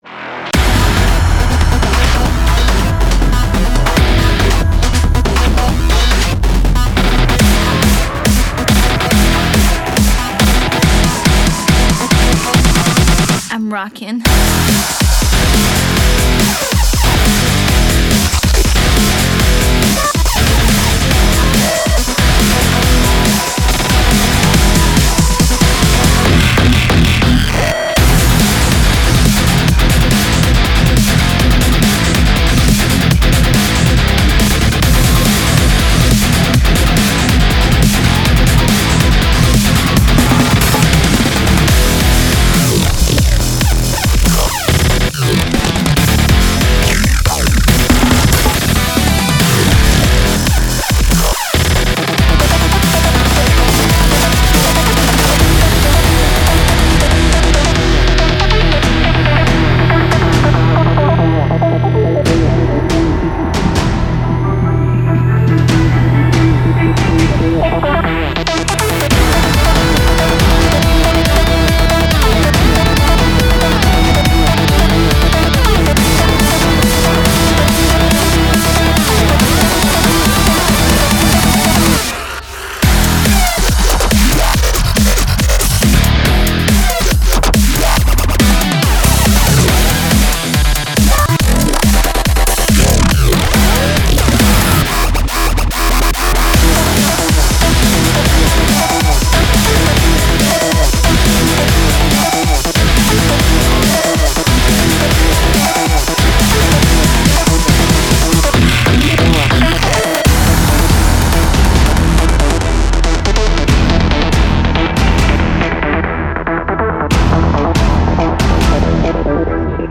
BPM140
Audio QualityPerfect (High Quality)